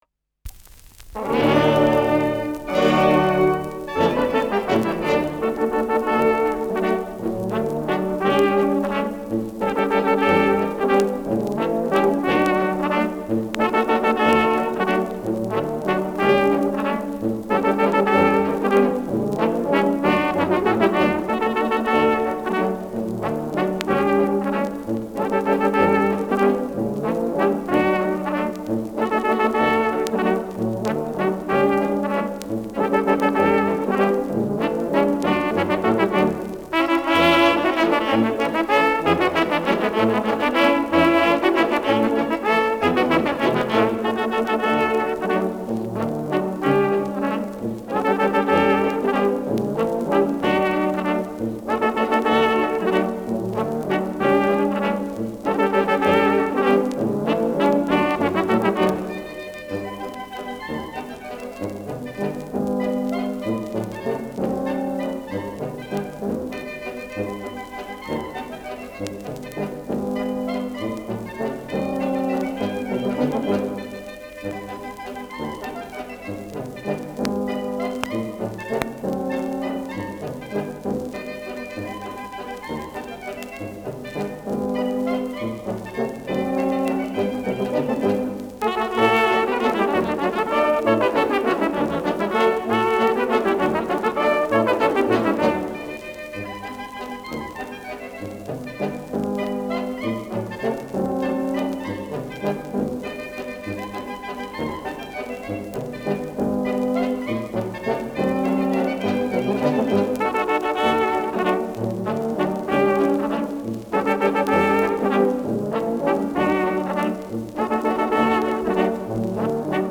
Schellackplatte
gelegentliches Knistern : vereinzeltes Knacken
Bischofshofner Trachtenkapelle (Interpretation)